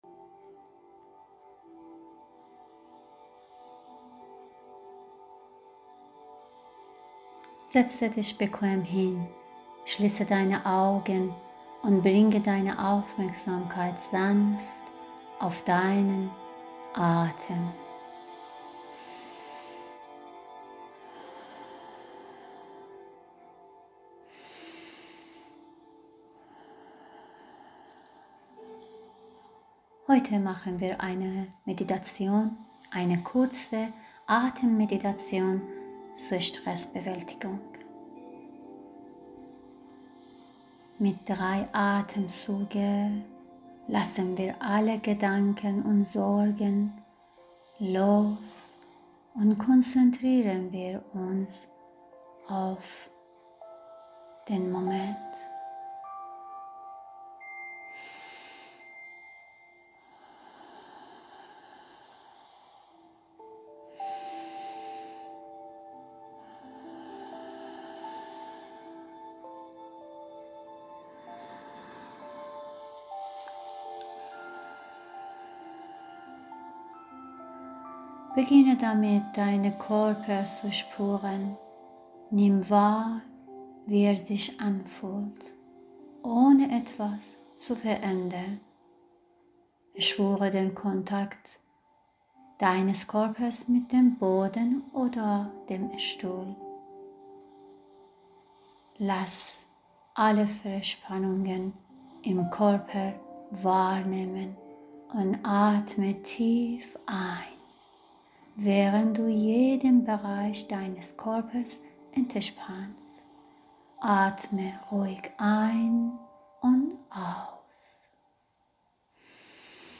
Kurze Atemmeditation zur Stressbewältigung (ca. 15 Minuten)